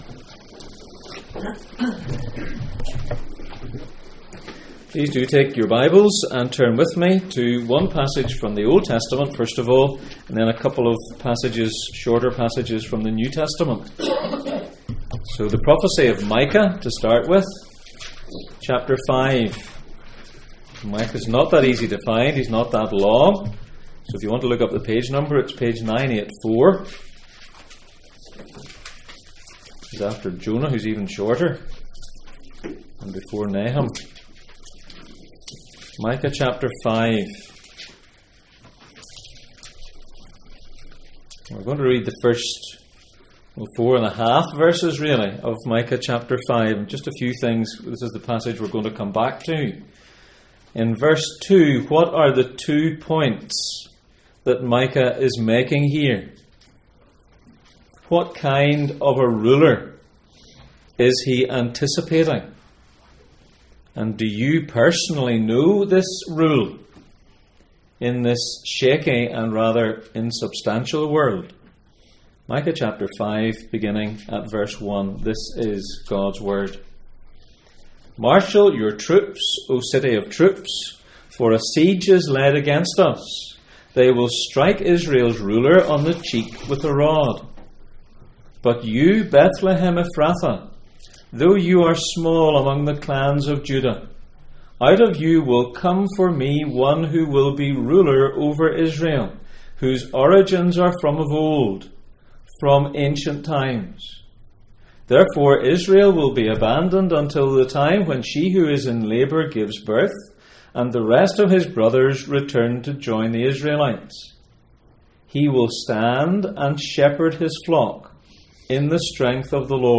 Passage: Micah 5:1-5, Matthew 2:1-6, John 10:4, John 10:11, John 10:14-15 Service Type: Sunday Morning